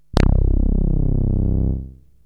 SYNTH BASS-1 0013.wav